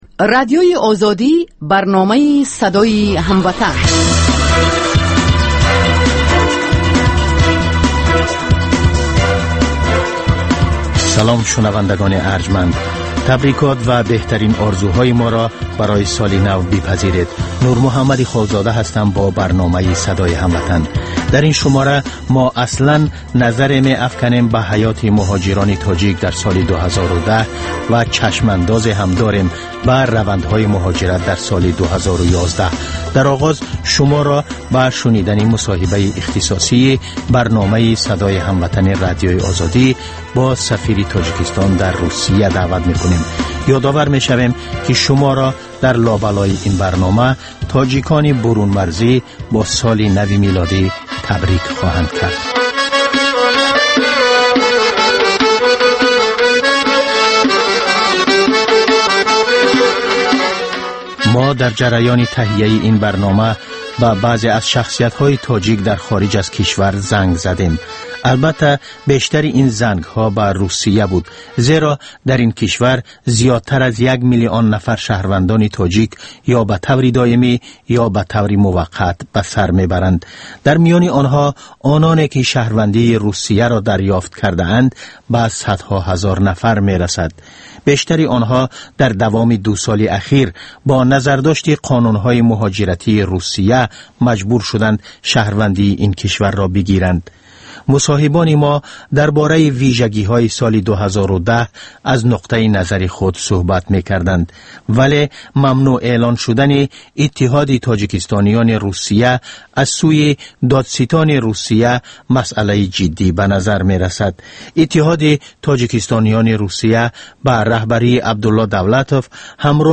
Гуфтугӯи ошкоро бо чеҳраҳои саршинос.